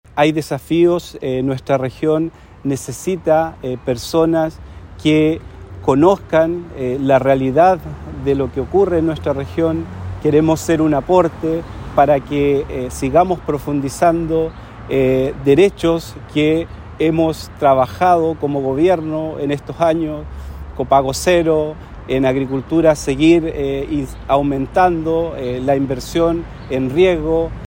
En su despedida al interior de las oficinas de la cartera que dirigió, dijo que buscará ser un aporte para profundizar políticas y derechos promovidos por el Gobierno.